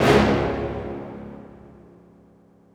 Metro Brass Hit.wav